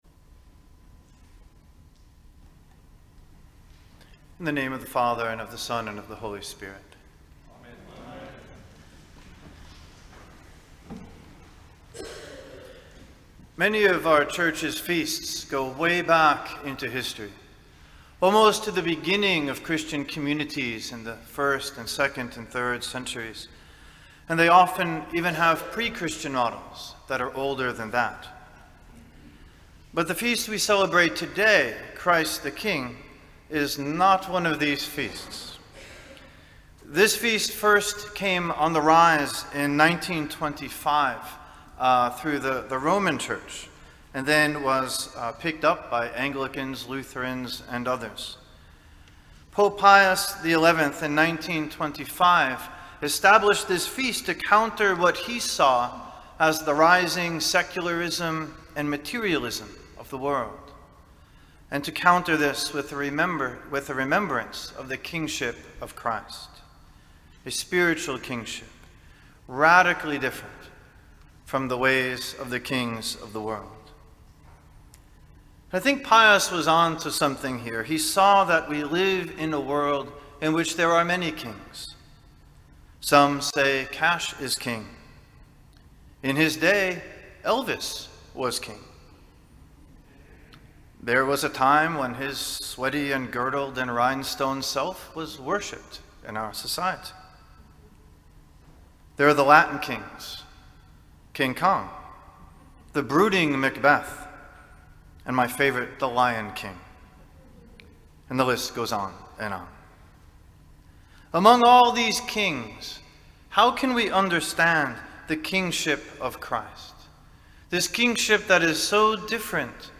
In today’s sermon